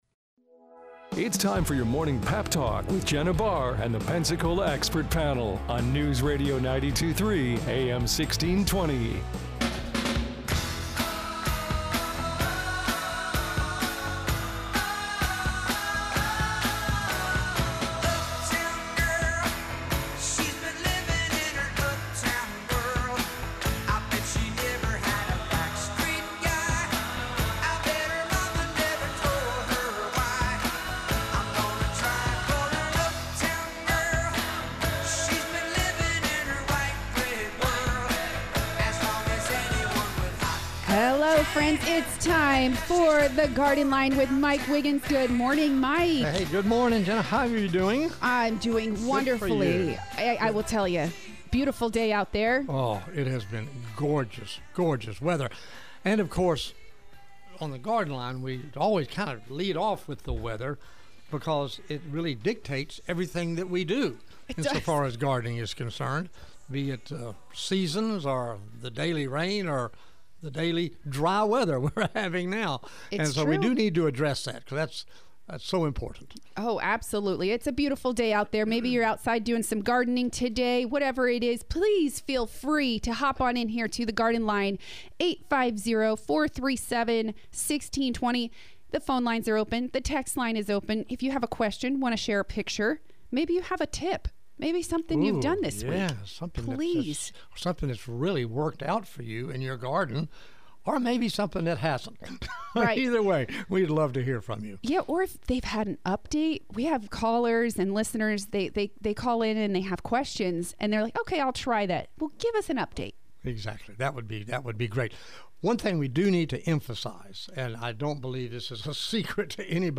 Callers chime in with advice and plenty of questions!